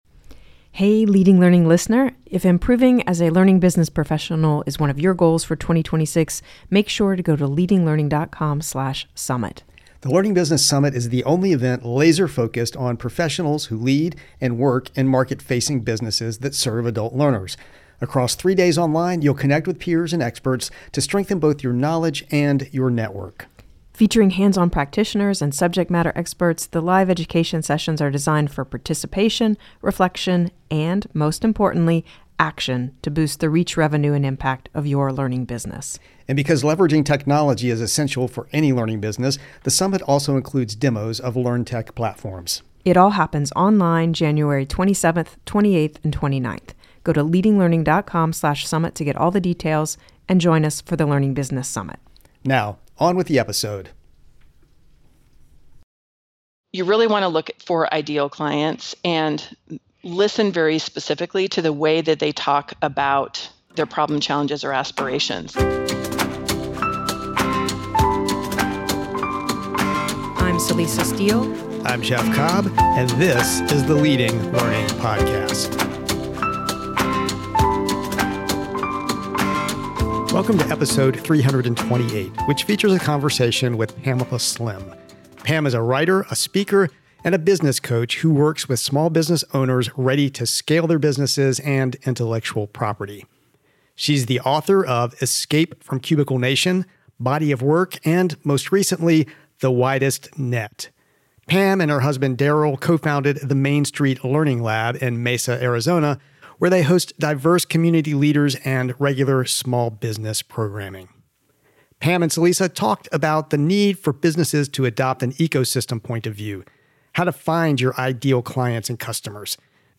a writer, a speaker, and a business coach